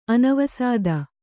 ♪ anavasāda